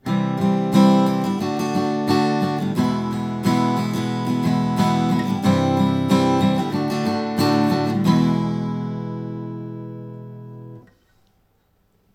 Rütmiharjutused (strum patterns)
10. Tüüpjärgnevus “kolm järjestikust mažoori”, a la Britney Spears “Lucky”: